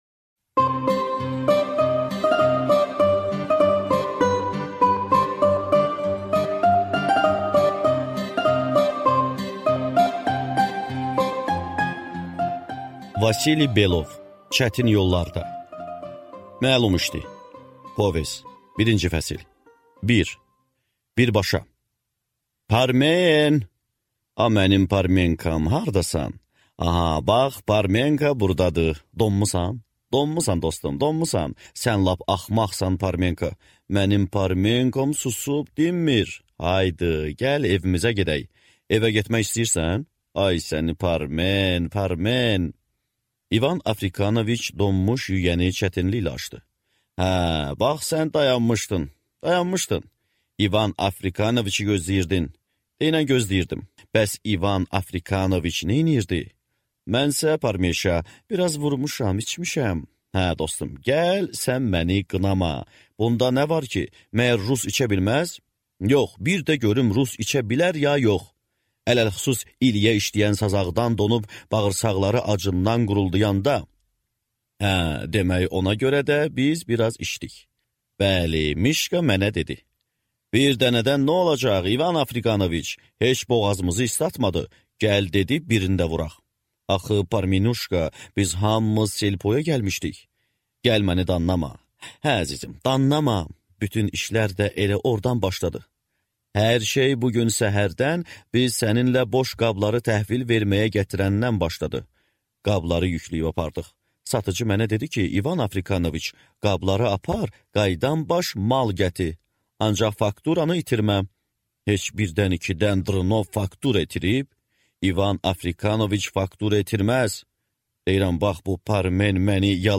Аудиокнига Çətin yollarda | Библиотека аудиокниг